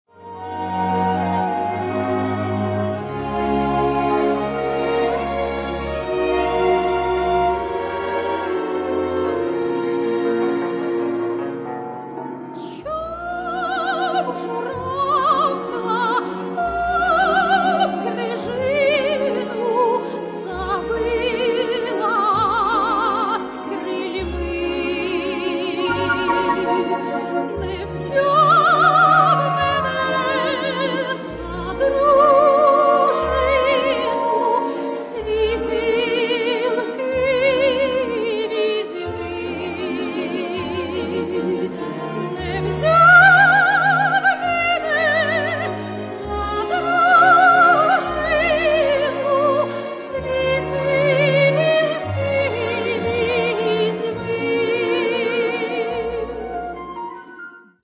Українські солоспіви